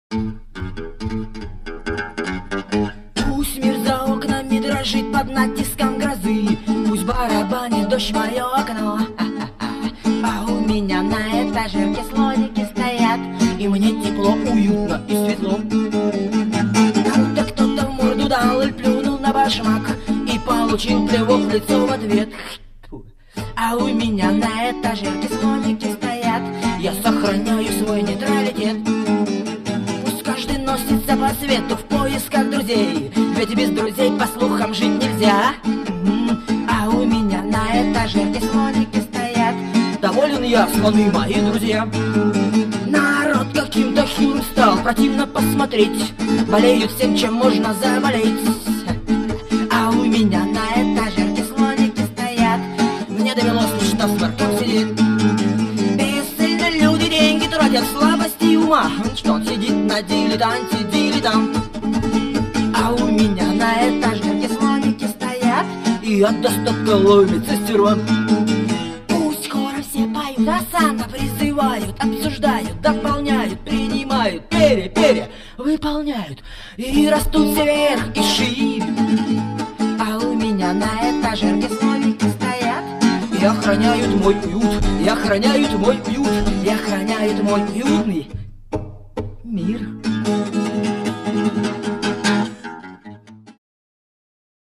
губная гармошка, голос.